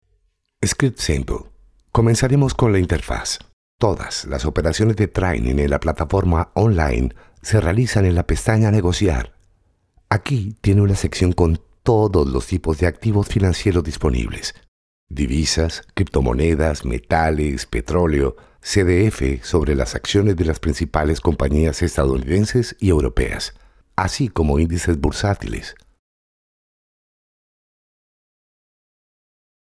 I can perform corporate, serious, deep and friendly voices
I am a professional broadcaster, I speak neutral Spanish, I am from Colombia.
kolumbianisch
Sprechprobe: eLearning (Muttersprache):